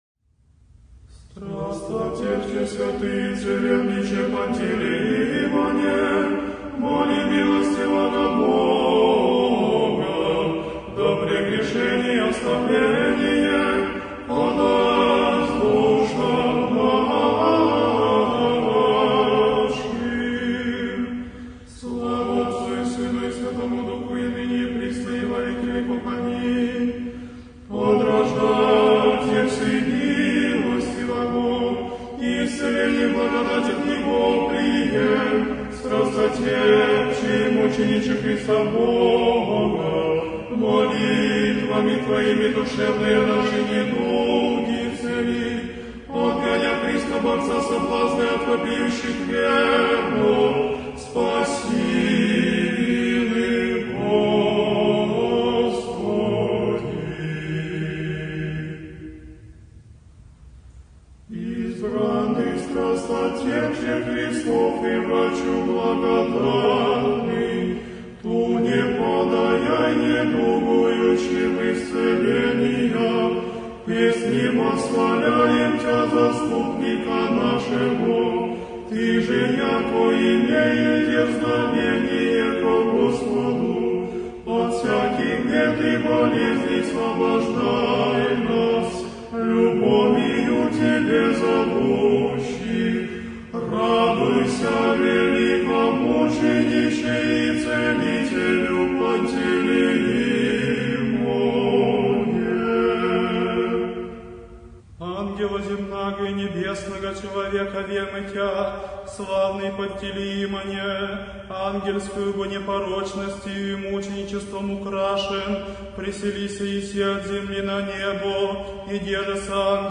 Молитва-и-акафист-Целителю-Пантелеймону-святому-великомученику-.-О-здравии.мирправославия.mp3